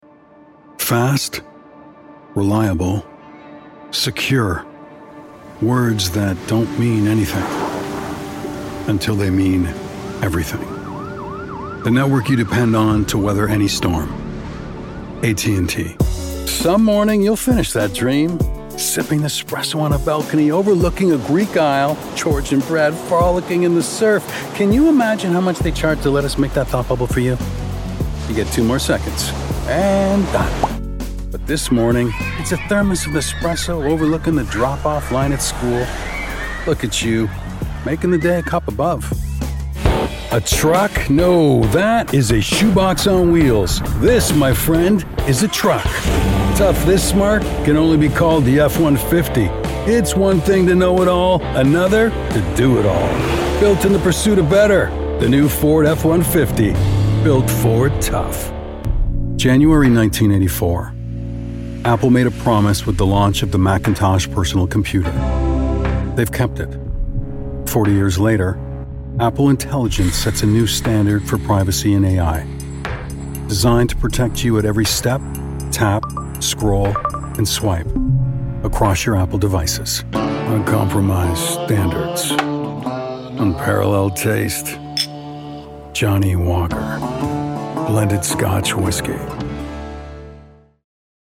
Friendly, Warm, Conversational.
Commercial